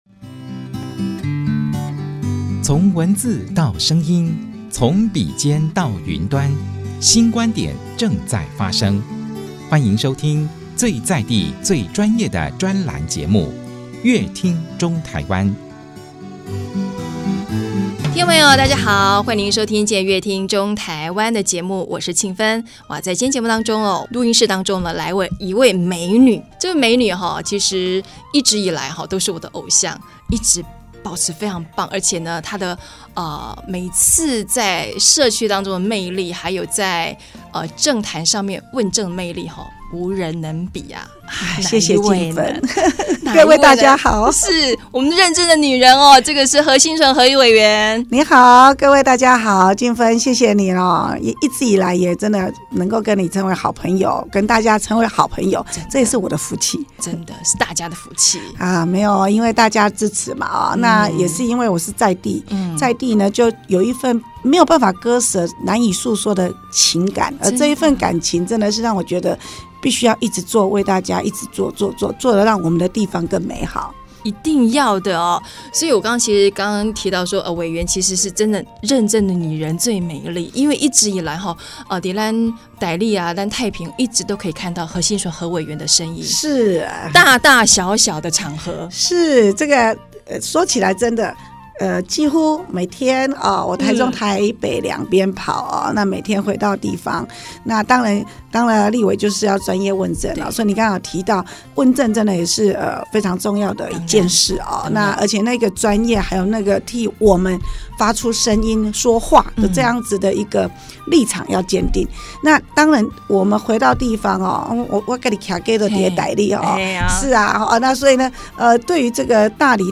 本集來賓：立法委員何欣純 本集主題：「生活中發現在地的服務哲學」 本集內容： 清晨五、六點，當大家都還在睡夢中